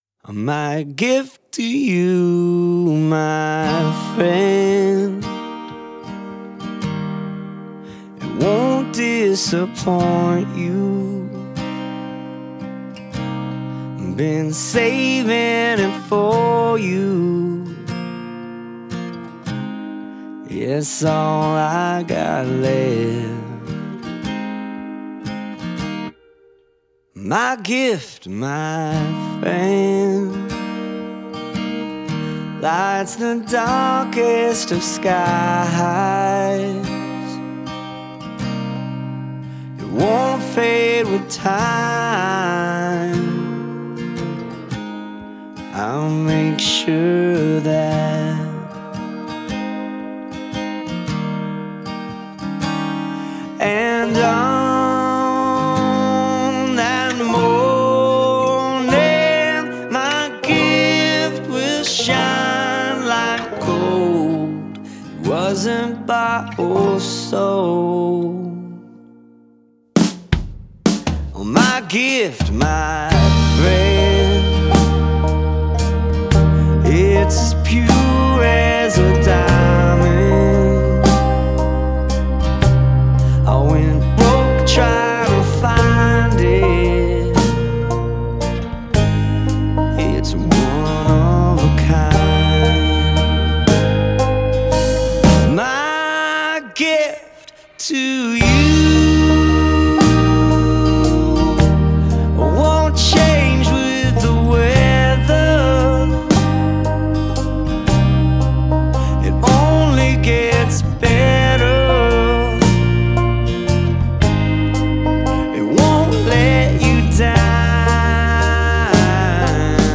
Original & traditional Christmas songs by indie artists, plus fave memories they share